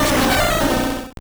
Cri de Roigada dans Pokémon Or et Argent.